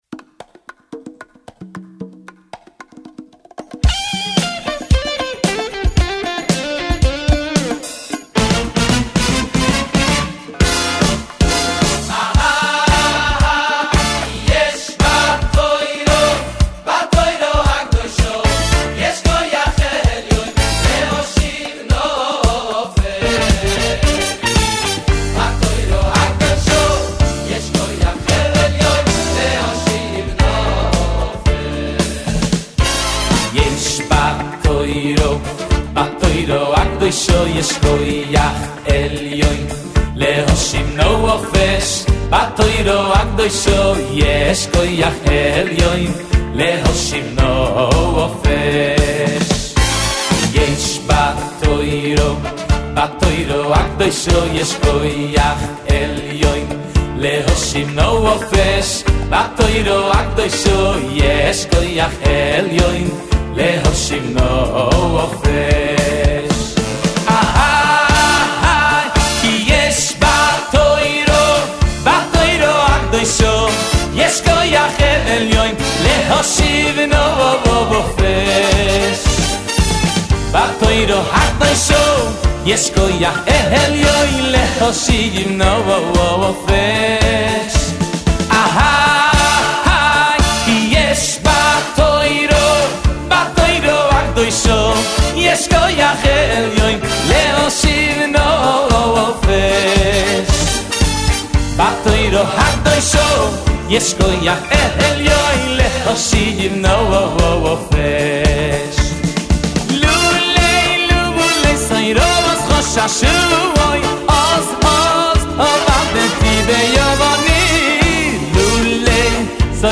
האם למישהו יש את השיר יש בתורה ששרו בסיום השס תודה רבה